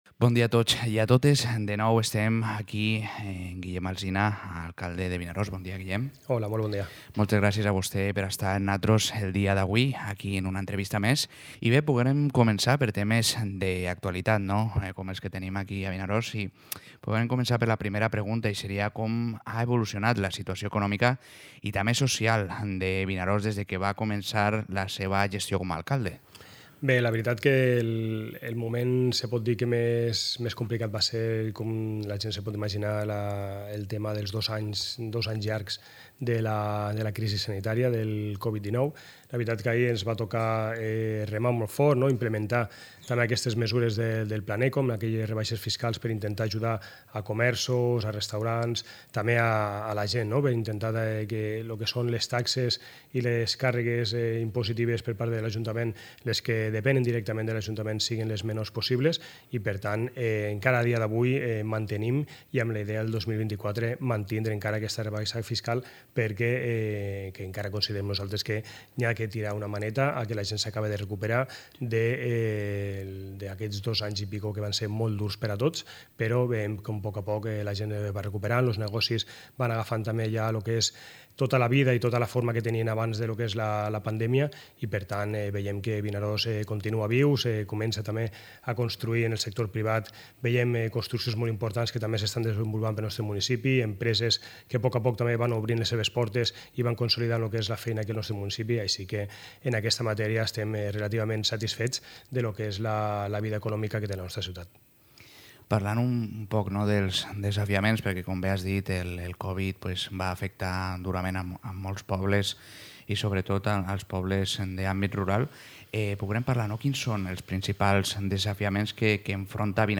Entrevista a l'alcalde de Vinaròs, Guillem Alsina